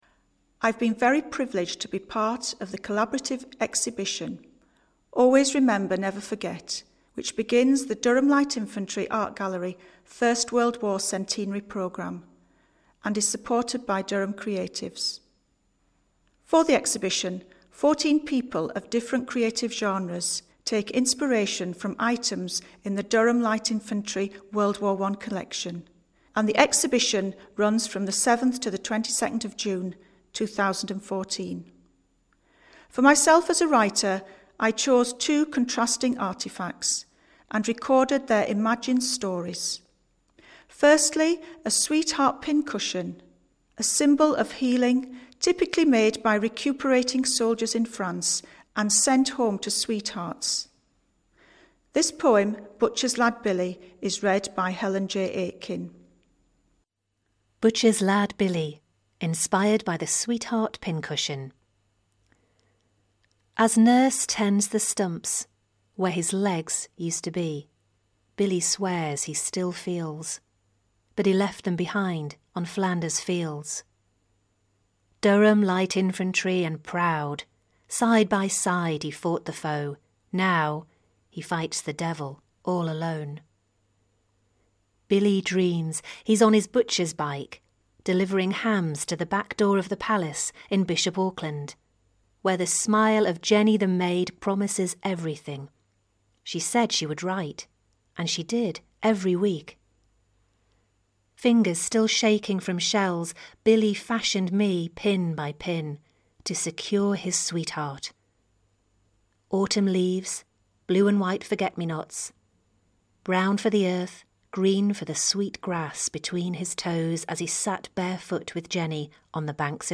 Listen to creative work inspired by handling objects from the Durham Light Infantry Museum WW1 collection. This was produced at creative writing workshops